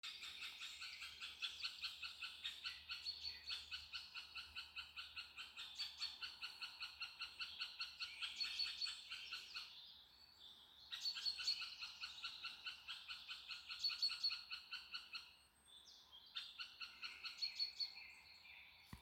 Birds -> Woodpeckers ->
Middle Spotted Woodpecker, Leiopicus medius
StatusAgitated behaviour or anxiety calls from adults